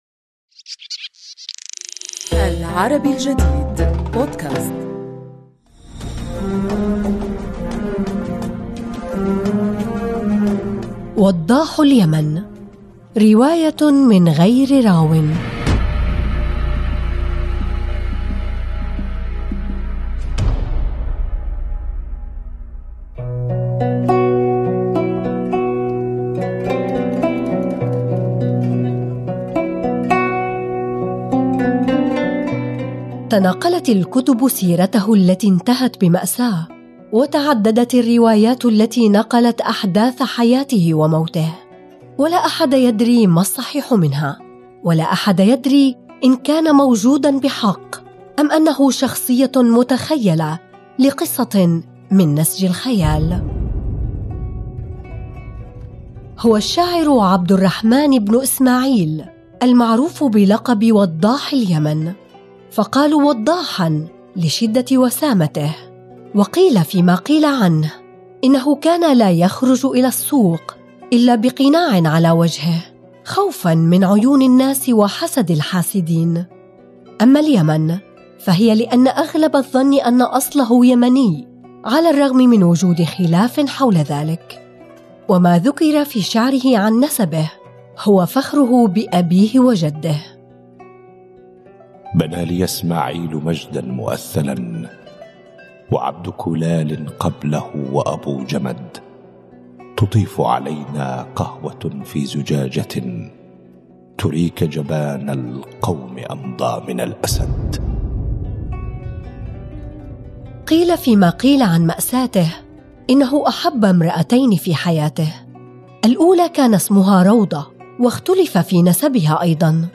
الراوي